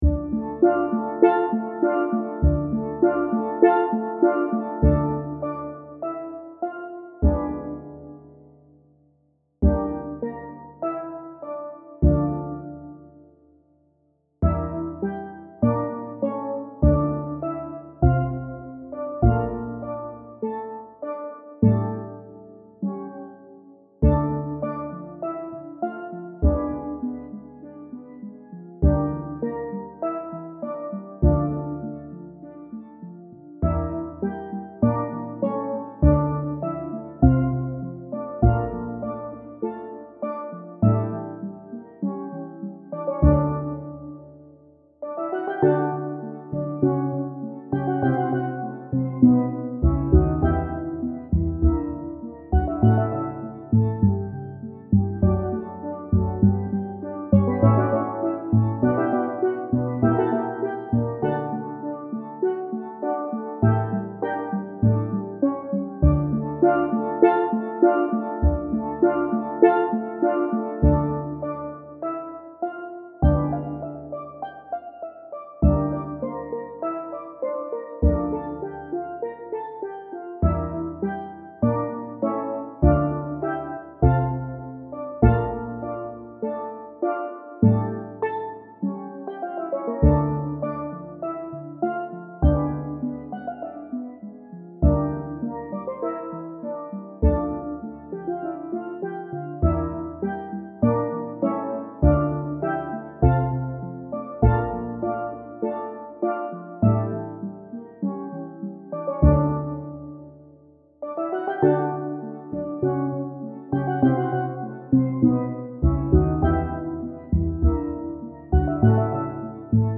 Steel Band Sound Files
These mp3's are up to tempo.